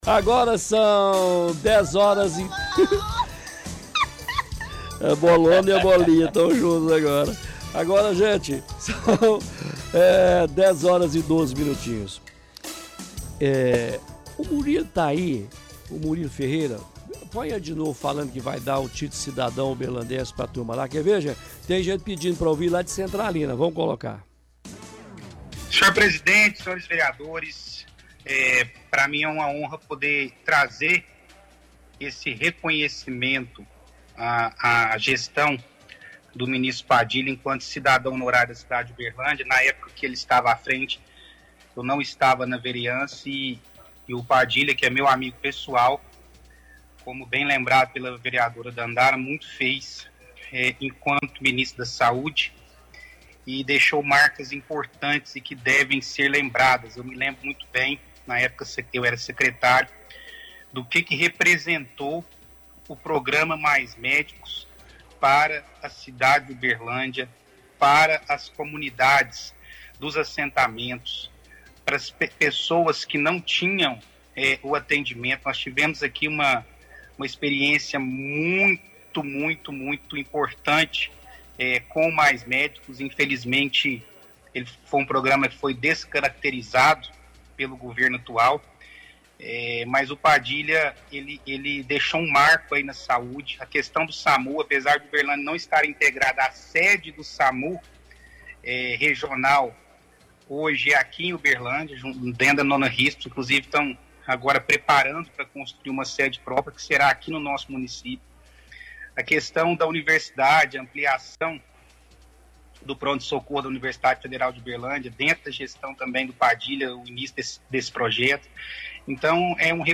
– Transmissão de áudio do vereador na câmara.